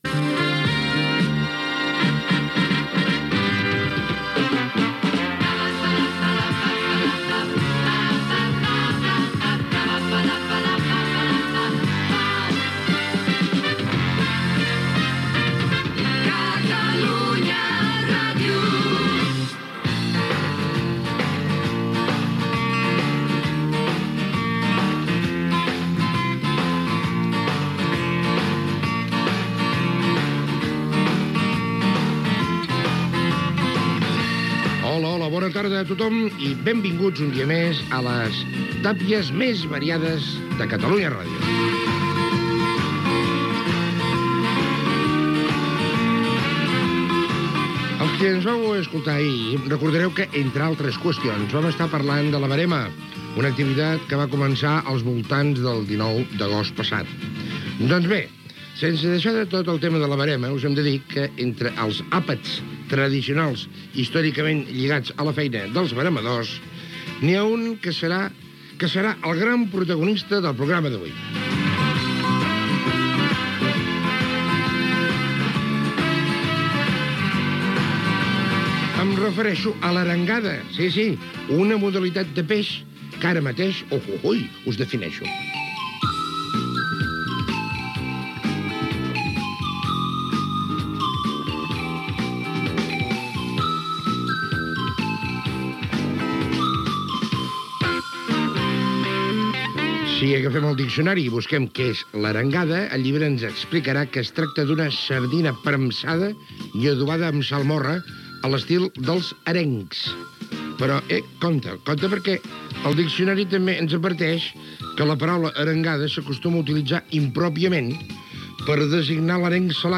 Indicatiu de l'emissora, sintonia
Divulgació